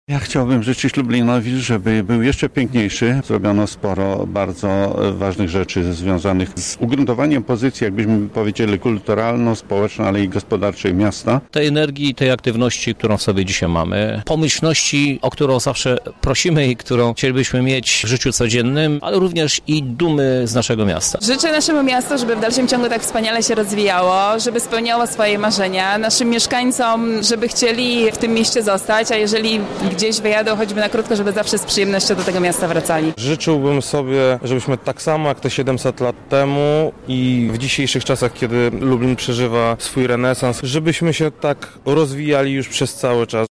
O życzenia dla Lublina poprosiliśmy przedstawicieli lubelskiej polityki, kultury i nauki: